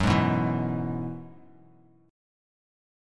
Gb7sus2sus4 chord